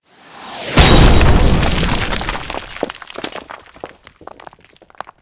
rocketgroundout.ogg